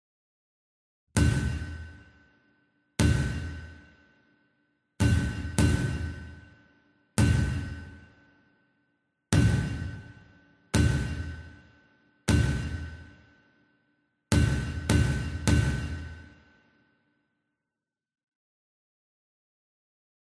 Cross Nailing Botón de Sonido